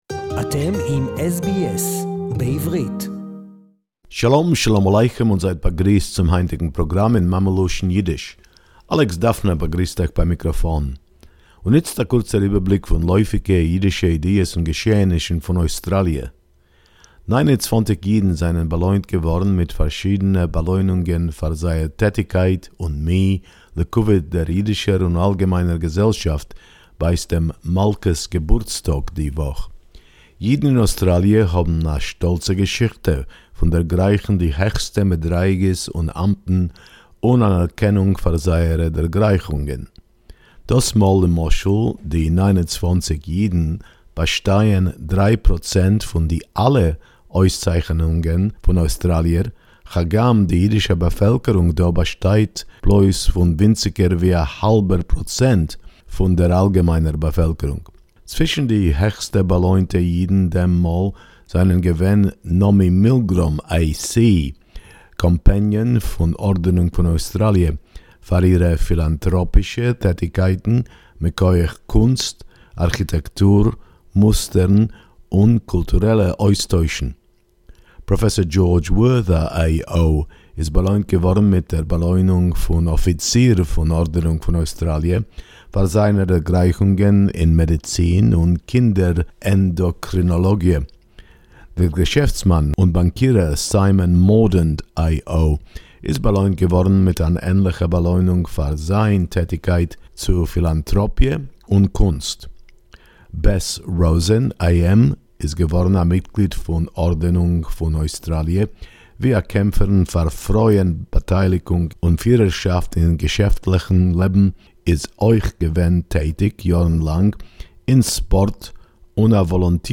29 Jewish Australians were awarded honours in the Queen’s Birthday honours’ list Yiddish report 14.6.2020